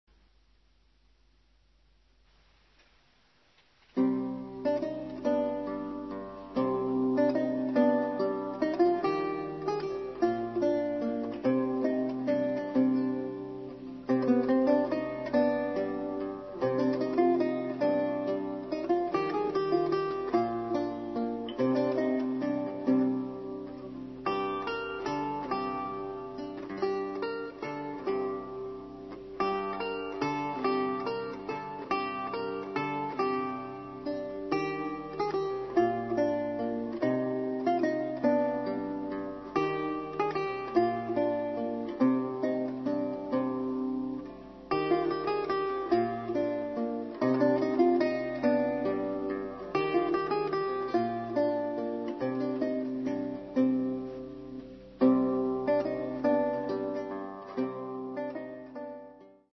A professional early music duo who specialise in performing the popular music of Elizabethan England, on authentic instruments, in period costume.